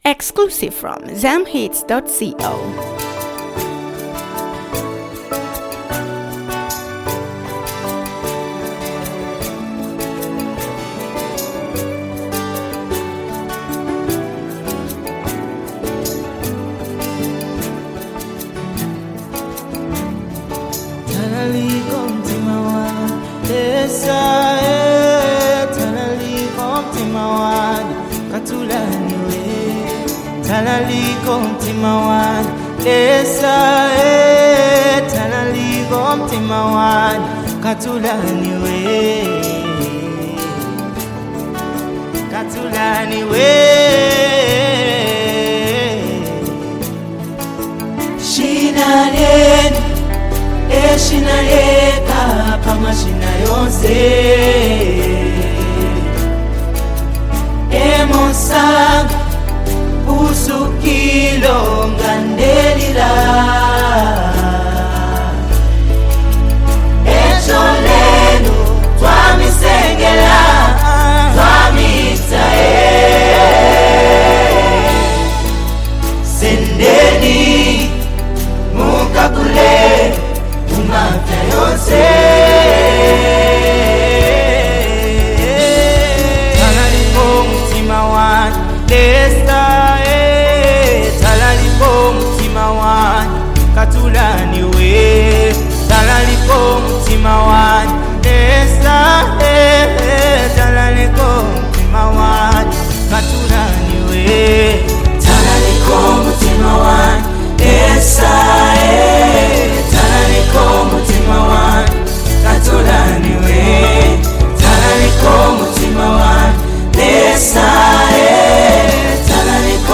This heartfelt worship song is a soulful and emotional track